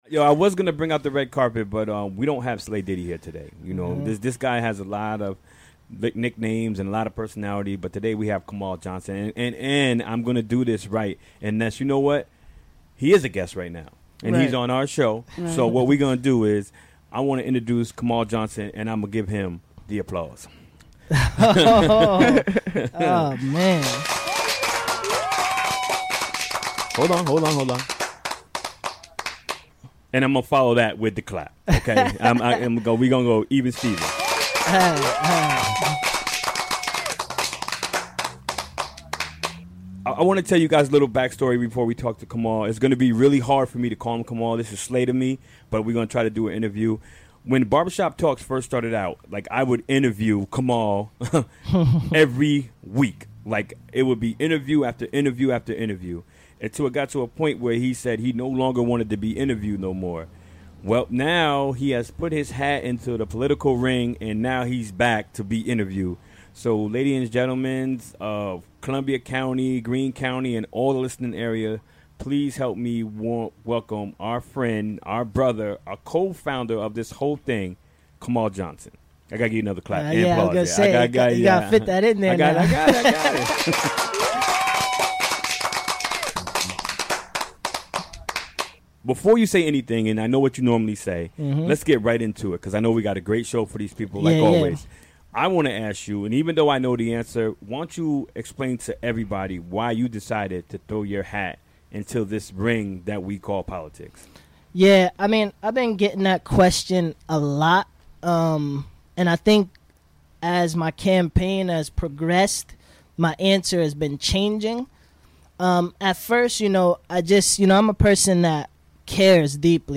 Interviewed
Recorded during the WGXC Afternoon Show Wednesday, August 9, 2017.